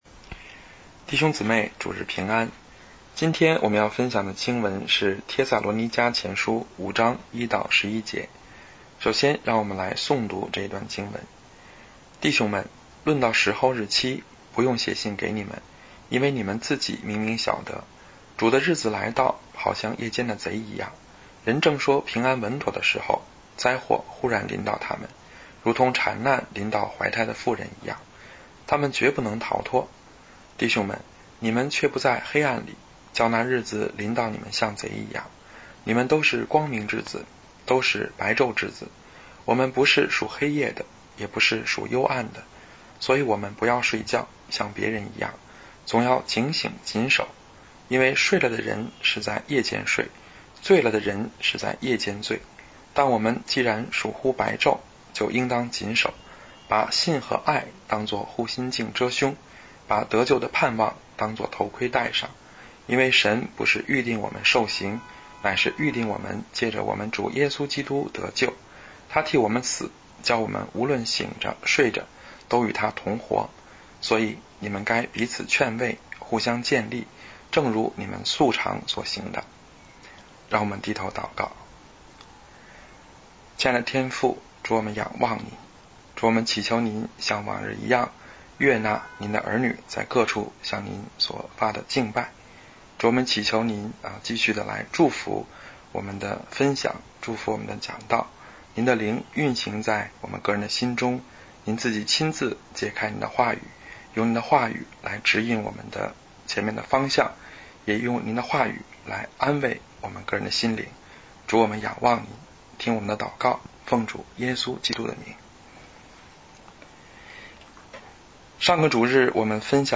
光明之子，白昼之子——2014年1月19日主日讲章